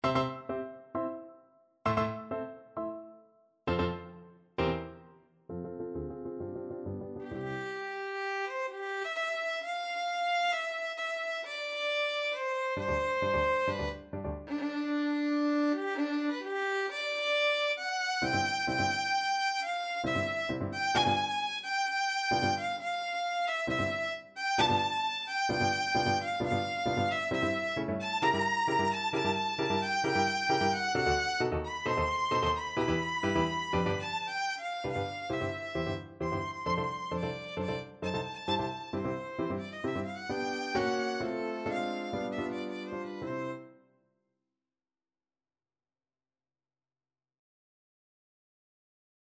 2/2 (View more 2/2 Music)
C major (Sounding Pitch) (View more C major Music for Violin )
Allegro agitato e appassionato assai = 132 (View more music marked Allegro)
Violin  (View more Intermediate Violin Music)
Classical (View more Classical Violin Music)